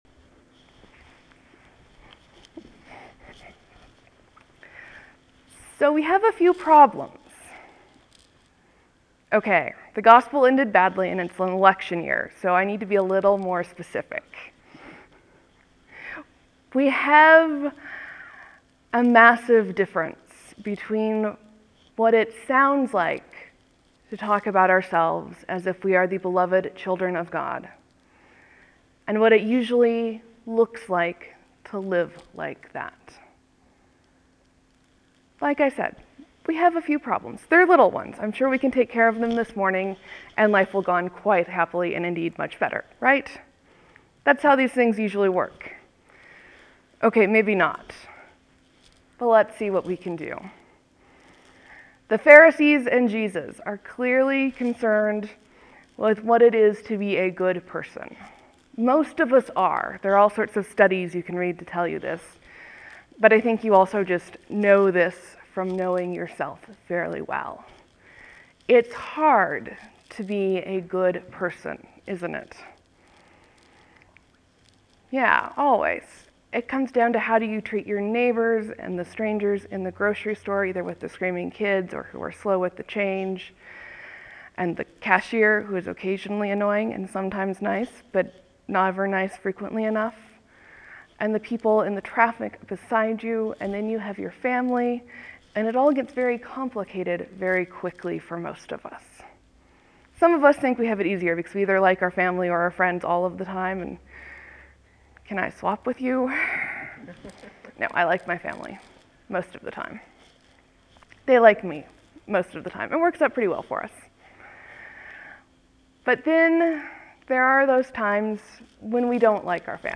(There will be a few moments of silence before the sermon starts.